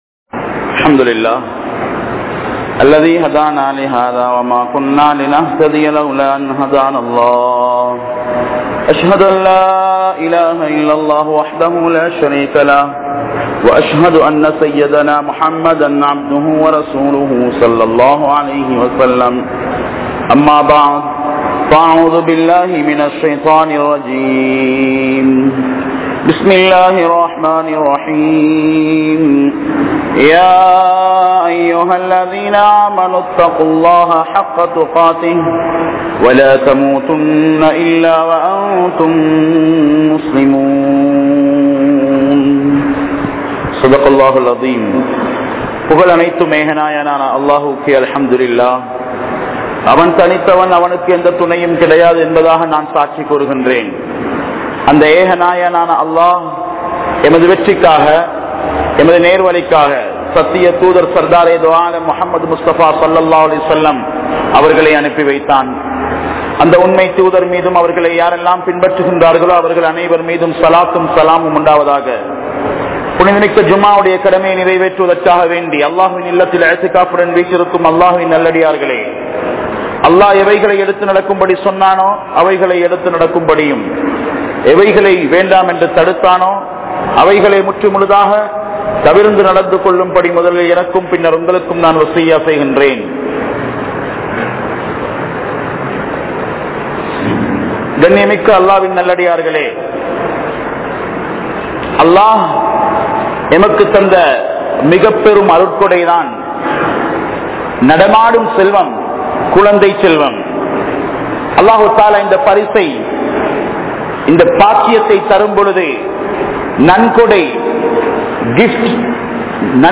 Petroarhal Pillaihalukku Seiya Maranthavaihal (பெற்றோர்கள் பிள்ளைகளுக்கு செய்ய மறந்தவைகள்) | Audio Bayans | All Ceylon Muslim Youth Community | Addalaichenai
Kandy,Malay Jumua Masjith